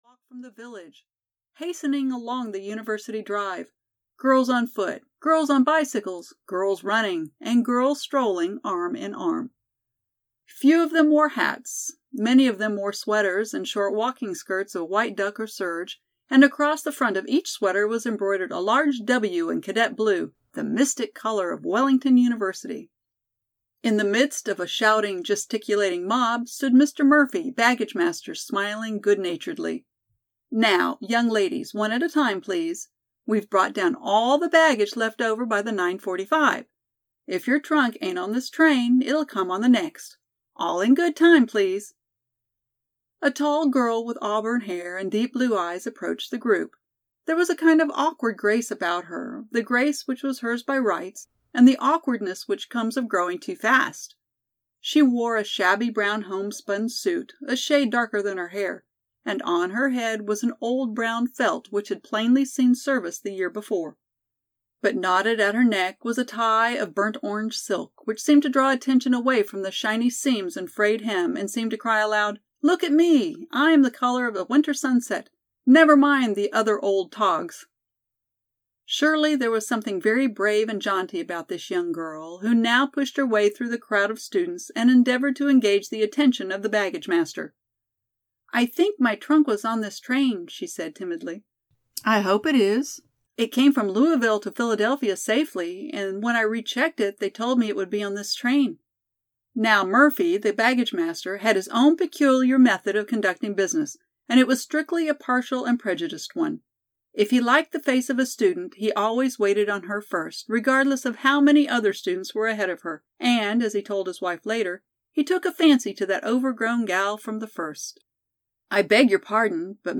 Molly Brown's Freshman Days (EN) audiokniha
Ukázka z knihy